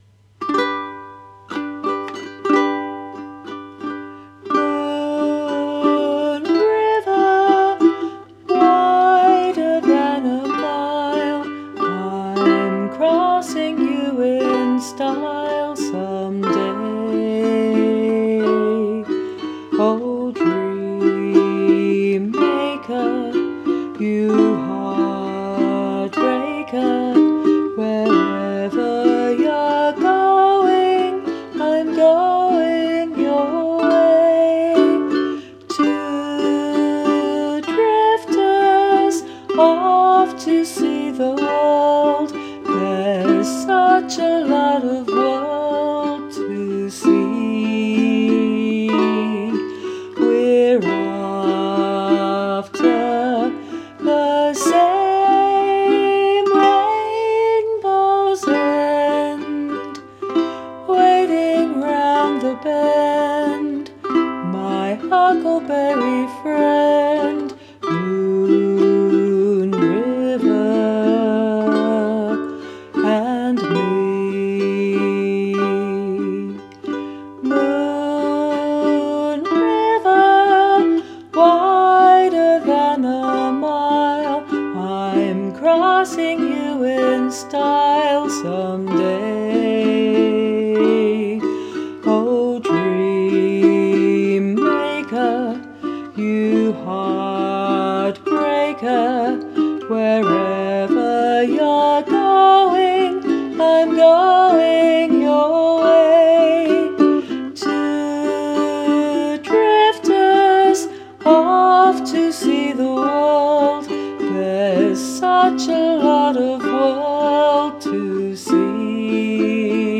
One take ukulele as with all my Christmas covers so far.